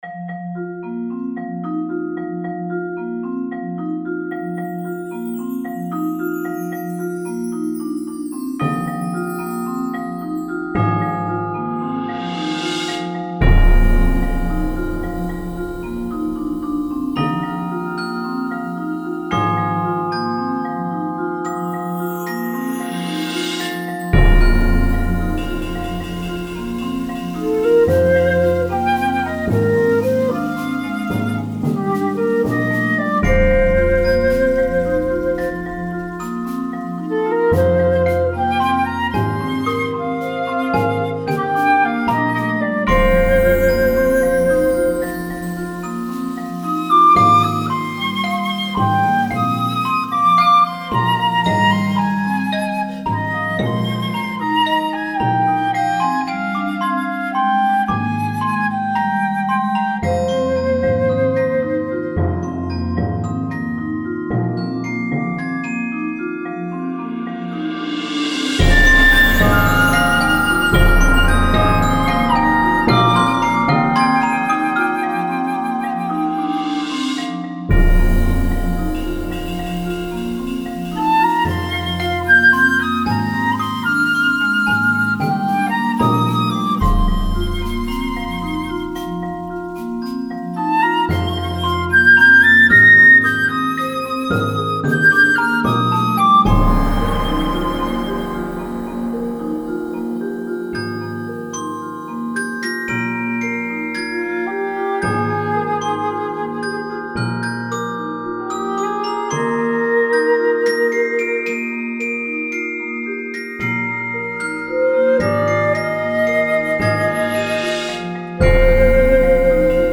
Voicing: 11 Percussion